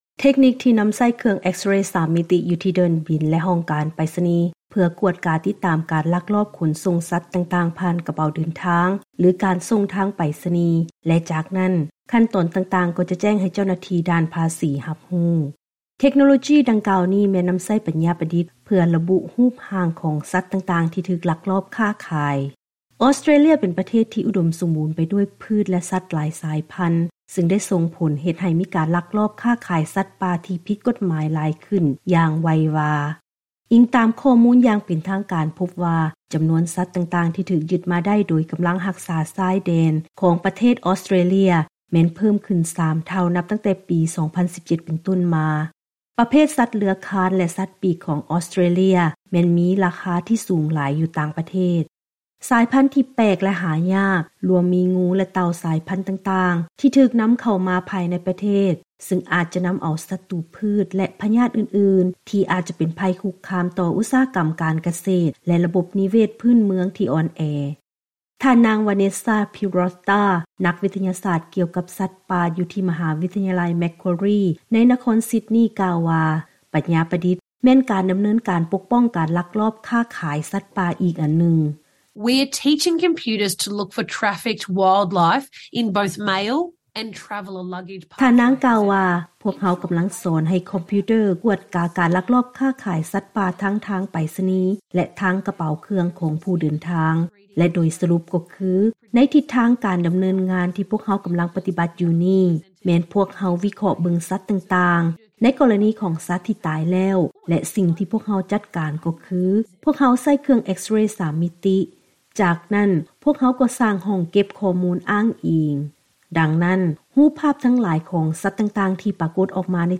ເຊີນຮັບຟັງລາຍງານກ່ຽວກັບ ການຜະລິດປັນຍາປະດິດຂອງ ອອສເຕຣເລຍ ເພື່ອຕໍ່ຕ້ານການລັກລອບຄ້າຂາຍສັດປ່າ